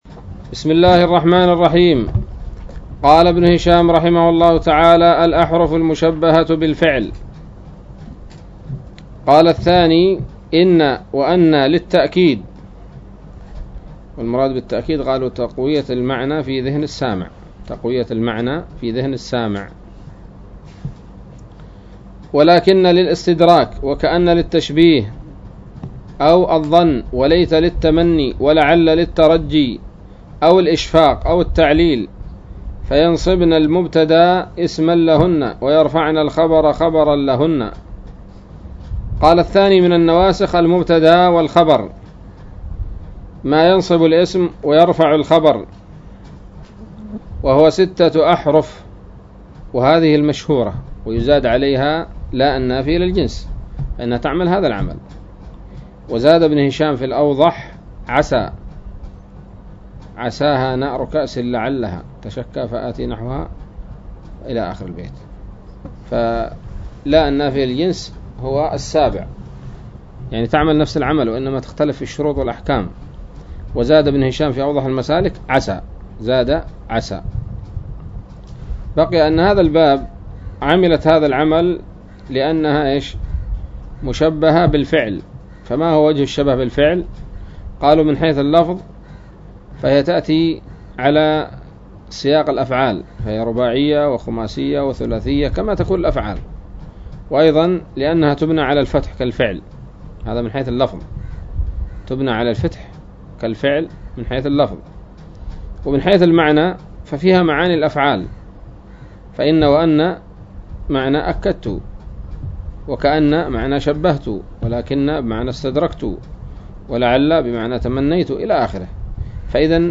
الدرس الثاني والستون من شرح قطر الندى وبل الصدى